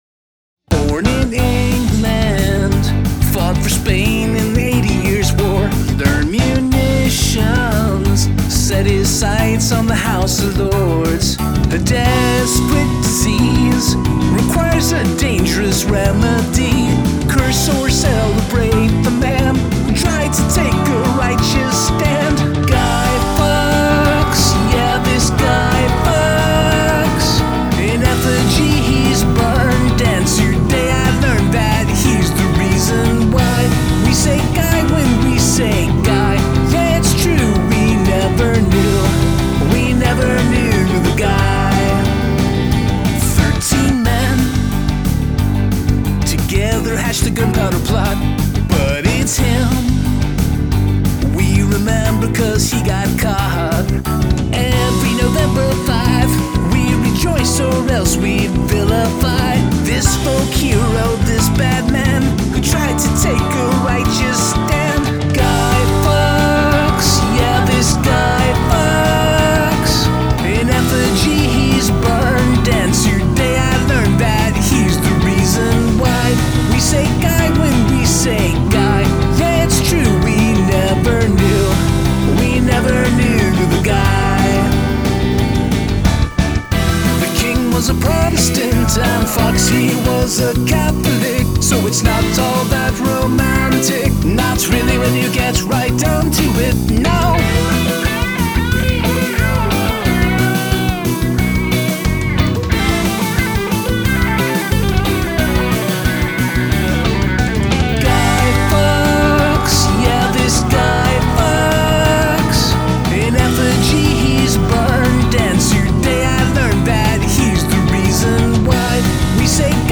This is very much a biographical song. Very catchy.
I like how poppy it is, and all the fast different turns it takes, that way it is never boring! Great vocal.